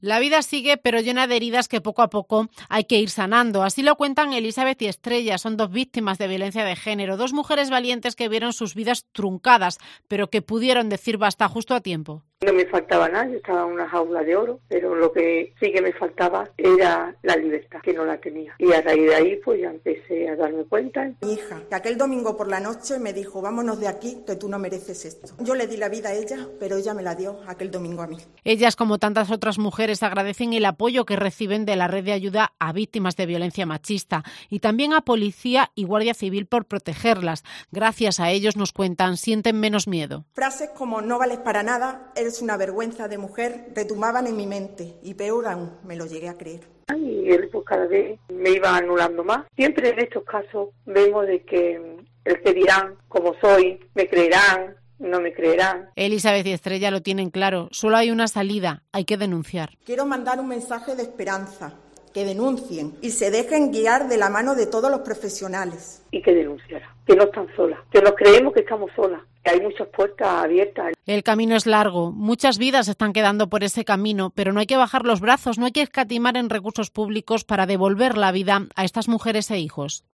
Testimonios víctimas de violencia de género